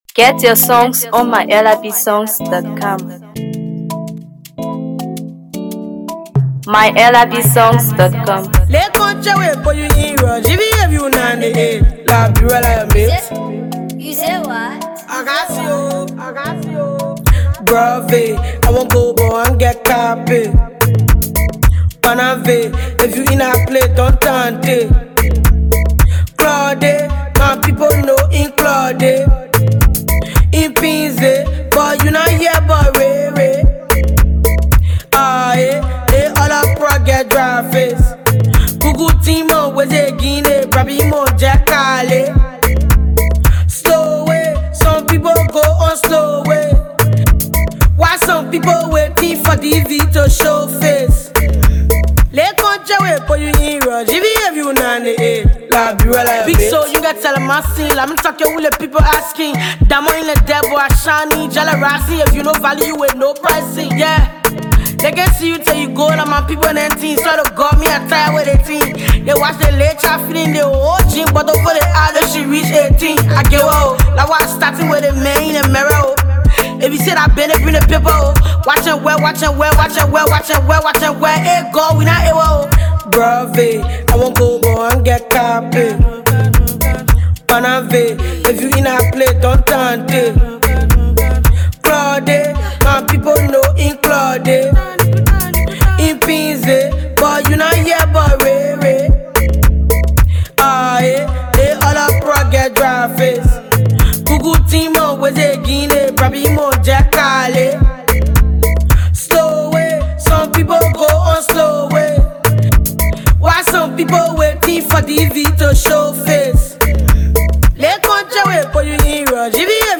Known for her unique blend of Afrobeat, Dancehall, and Hipco